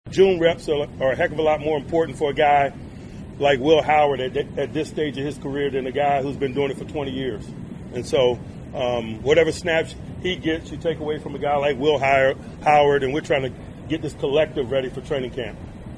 Tomlin says the minicamp is more about getting on-field work for young guys like his sixth round draft choice, former Ohio State quarterback Will Howard, than it is about Rodgers.